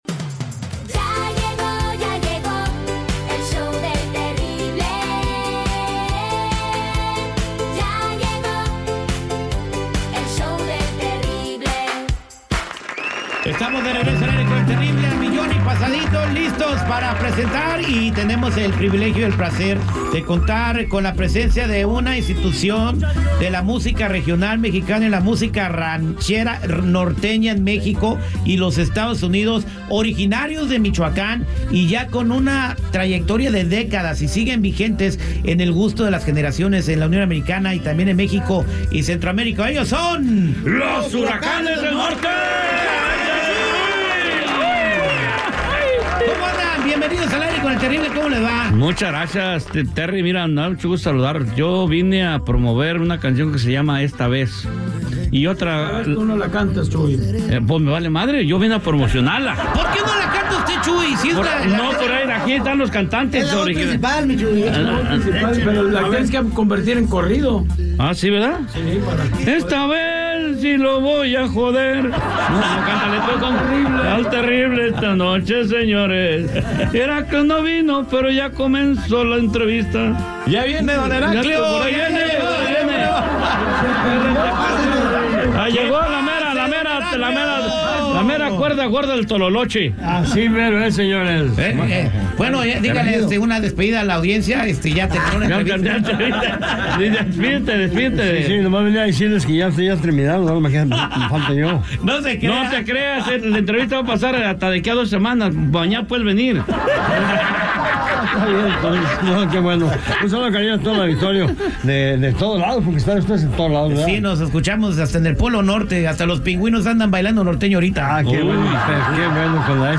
Entrevista Con Los Huracanes Del Norte....